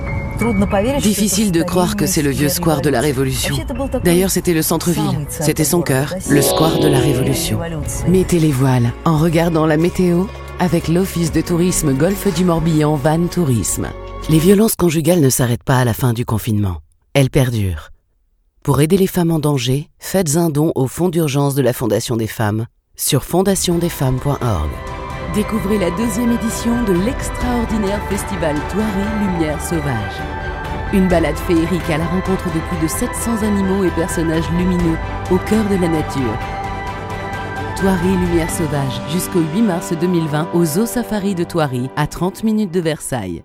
French European Voice Over Actors
Adult (30-50)
Both male and female native French speakers offer professional recordings from broadcast quality studios saving you money and time.